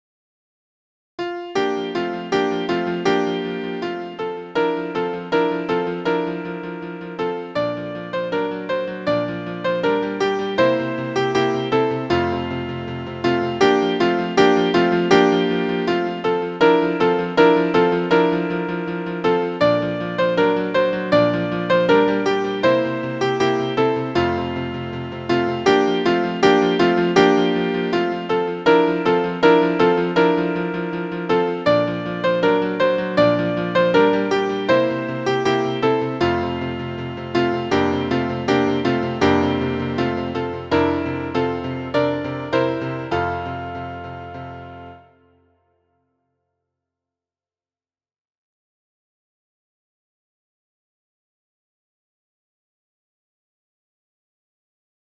Horizontal accents and syncopation for a stormy song.
• Key: F Major
• Time: 2/4
• Form: ABCD